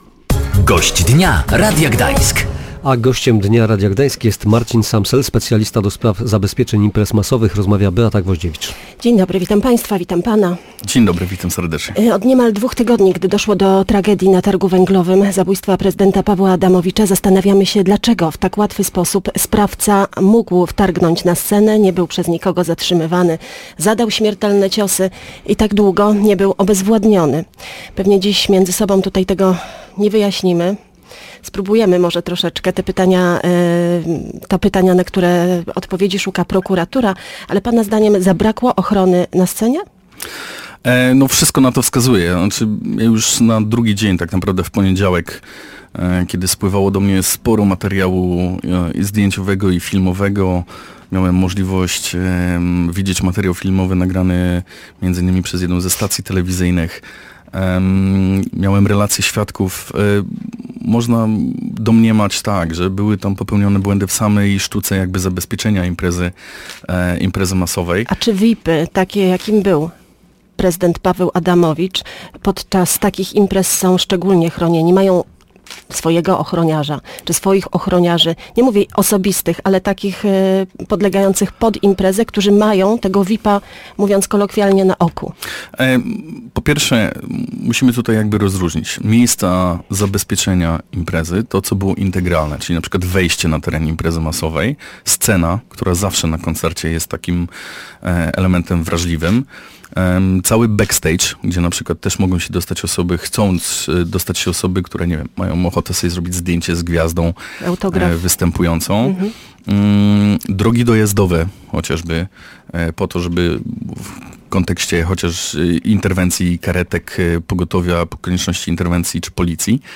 /audio/dok2/gd250119.mp3 Tagi: audycje Gość Dnia Radia Gdańsk